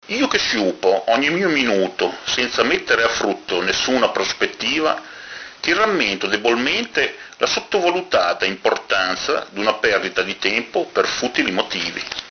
legge le sue poesie